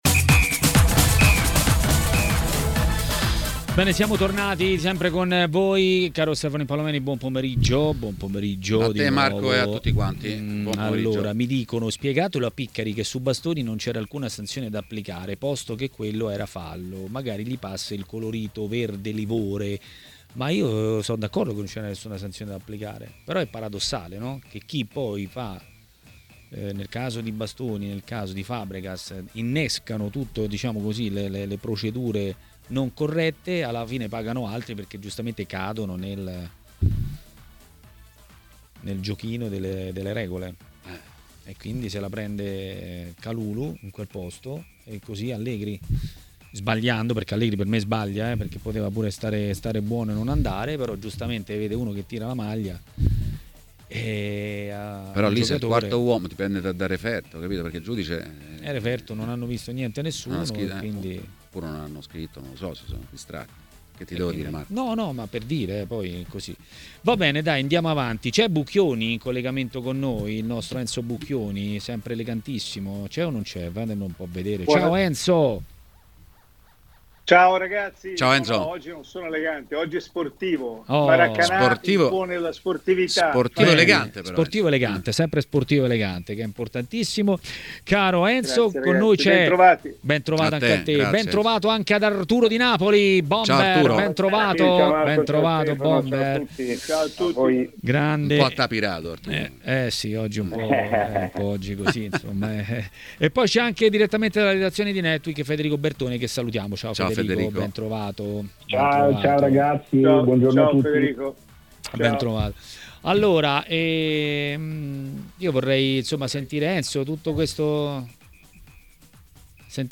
ha parlato dei temi del giorno a TMW Radio, durante Maracanà.